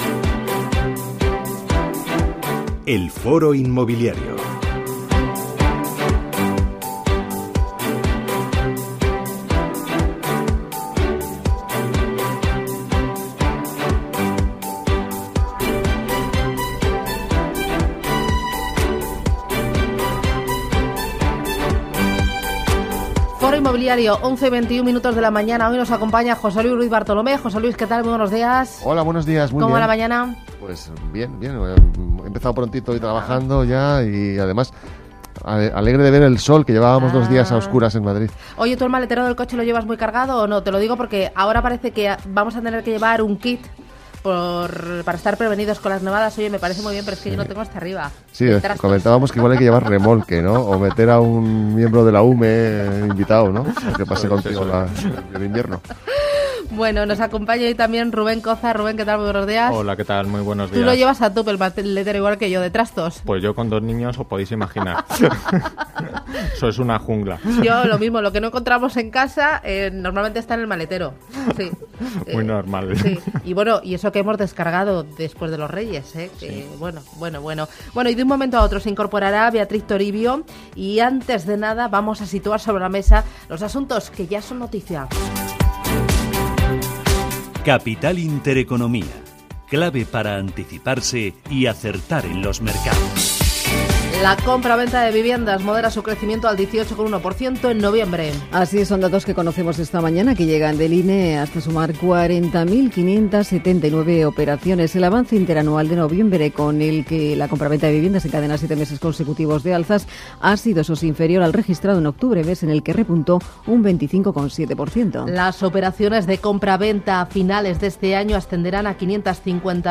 Expertos del sector